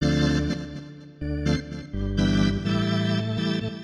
ORGAN014_VOCAL_125_A_SC3(L).wav
1 channel